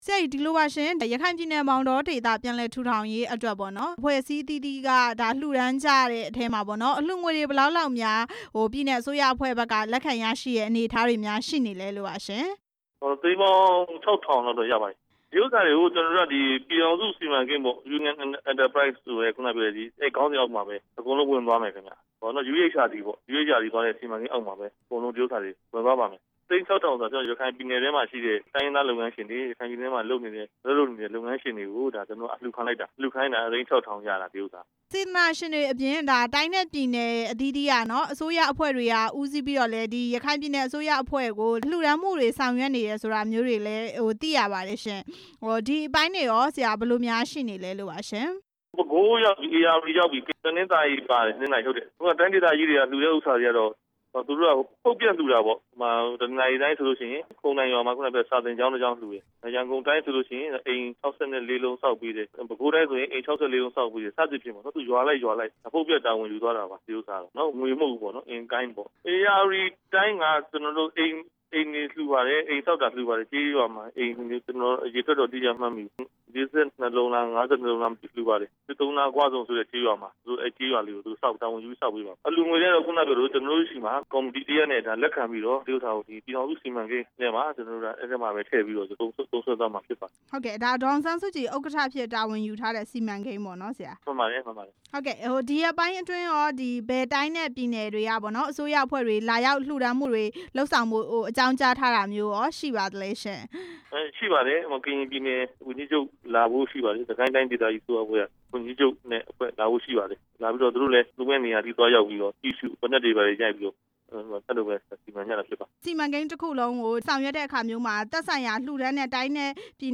မောင်တောဒေသ ပြန်လည်ထူထောင်ရေး အကူအညီ မေးမြန်းချက်